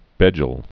(bĕjəl)